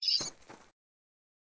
PixelPerfectionCE/assets/minecraft/sounds/mob/rabbit/hop4.ogg at mc116